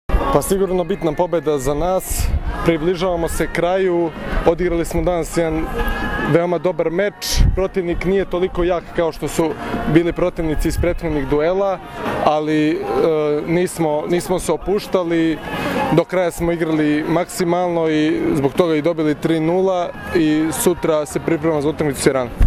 IZJAVA UROŠA KOVAČEVIĆA